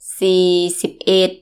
_ sii _ sibb _ edd